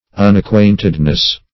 Unacquaintedness \Un`ac*quaint"ed*ness\, n.